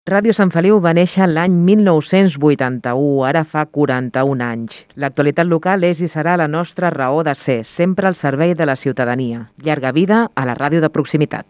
Enregistrament fet amb motiu del Dia Mundial de la Ràdio 2022.